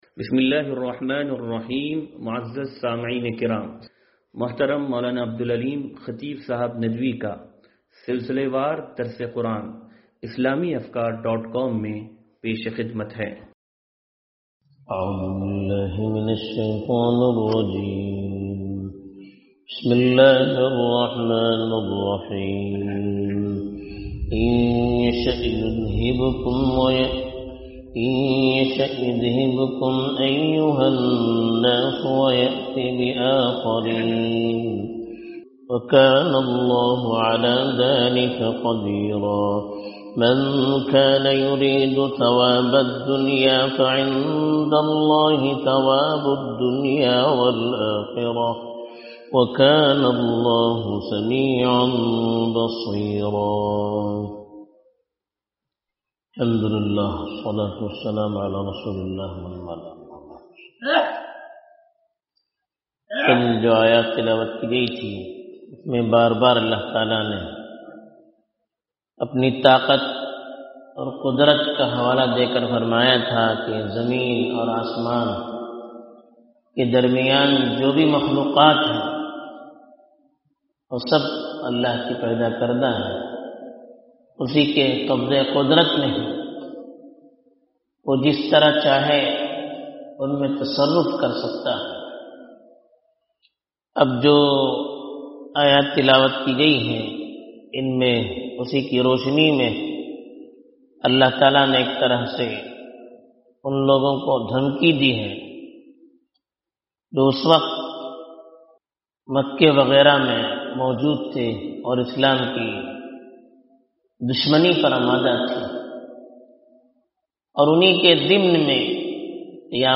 درس قرآن نمبر 0402